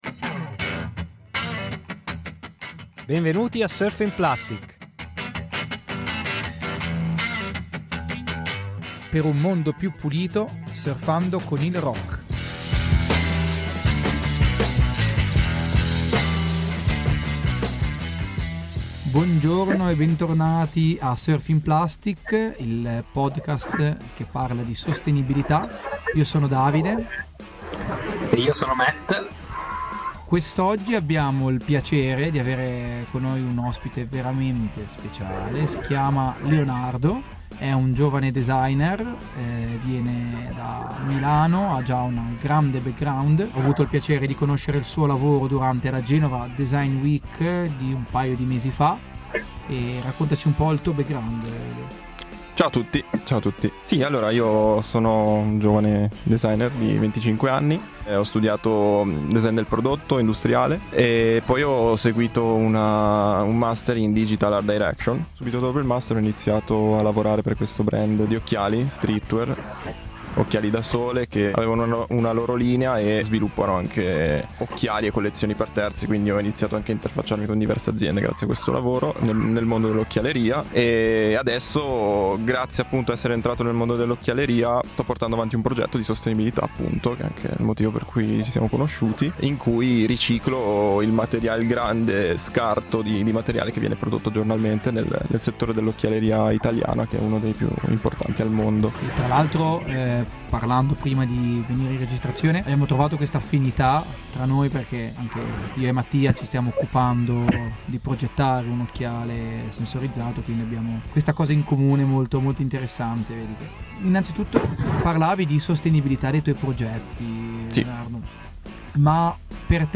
Canzone di sottofondo